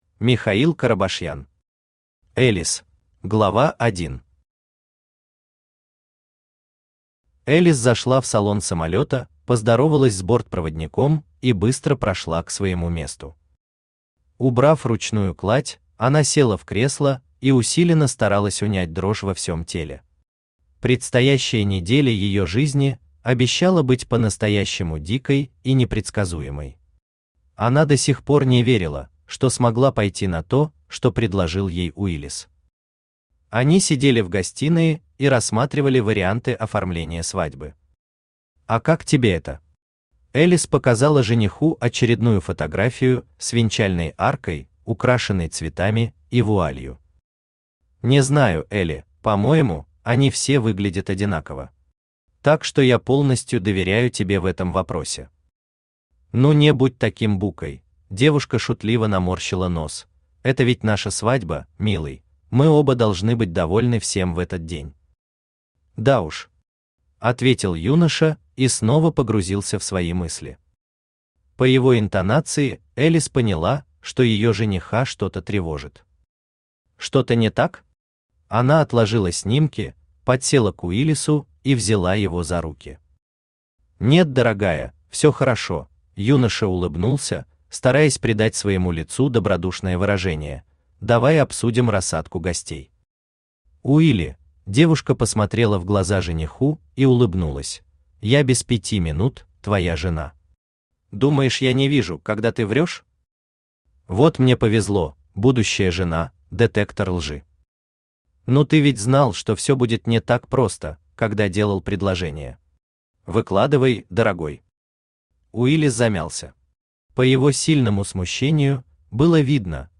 Aудиокнига Элис Автор Михаил Семёнович Карабашьян Читает аудиокнигу Авточтец ЛитРес.